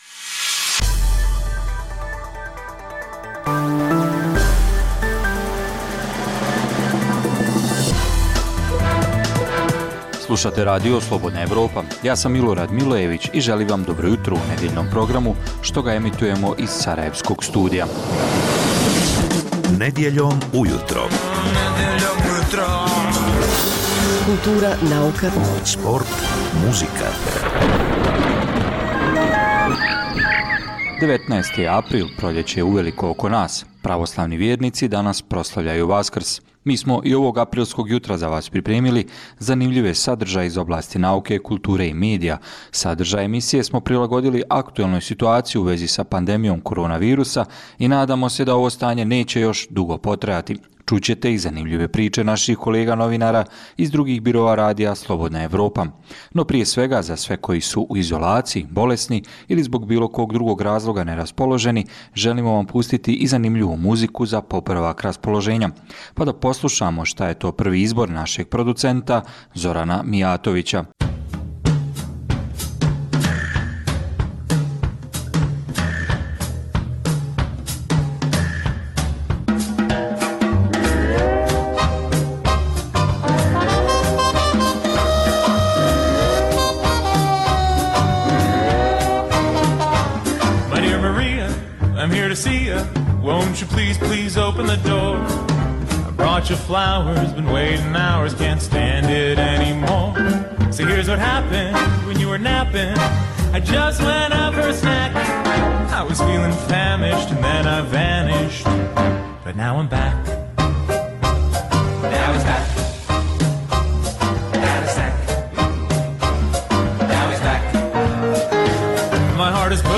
Nedjeljni jutarnji program za Bosnu i Hercegovinu. Poslušajte intervju sa zanimljivim gostom i, uz vijesti i muziku, pregled novosti iz nauke i tehnike, te čujte šta su nam pripremili novinari RSE iz regiona.